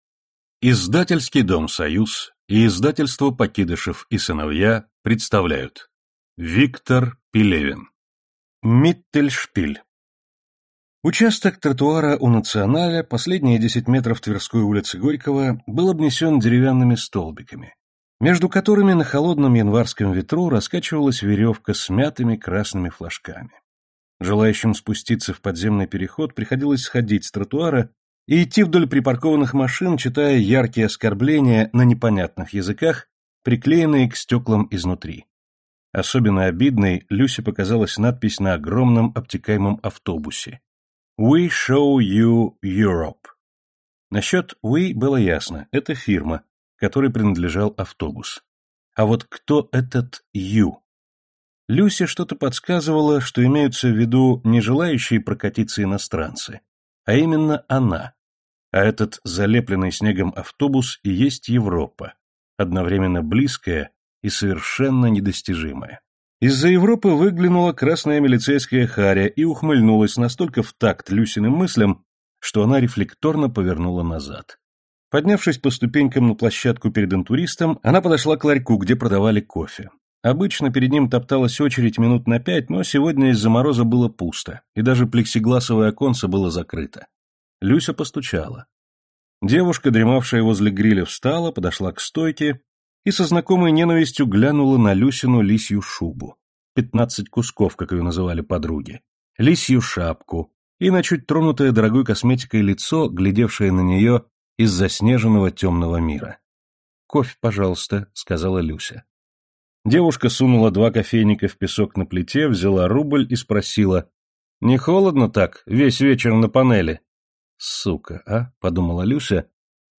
Аудиокнига Миттельшпиль | Библиотека аудиокниг
Aудиокнига Миттельшпиль Автор Виктор Пелевин Читает аудиокнигу Всеволод Кузнецов.